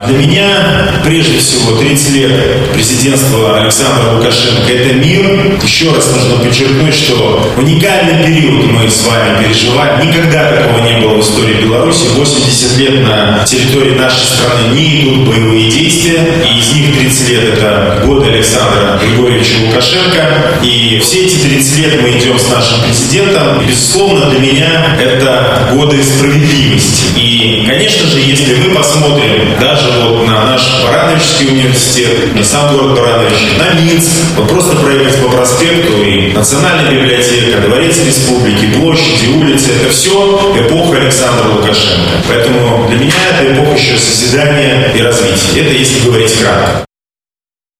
В этом году темой выбрана — «Мирная страна: истоки и перспективы белорусской модели развития».Диалог, который призван объединять белорусов, состоялся в Барановичах: площадкой выступил Барановичский государственный университет.